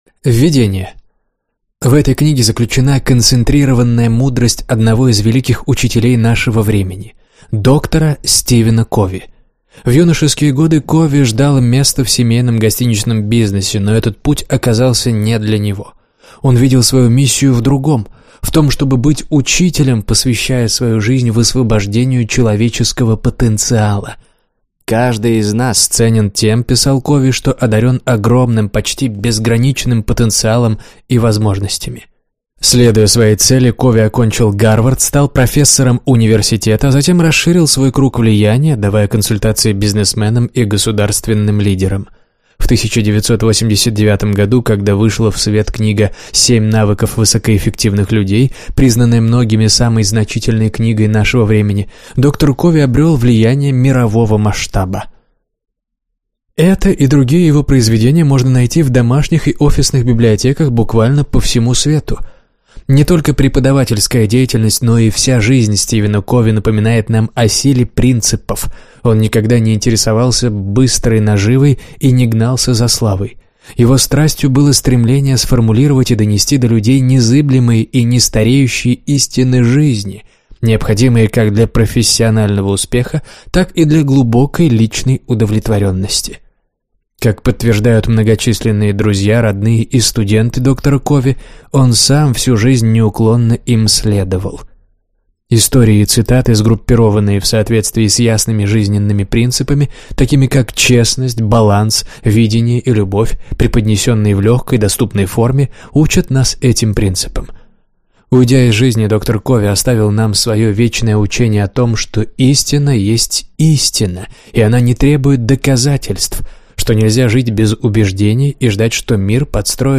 Аудиокнига Самое важное | Библиотека аудиокниг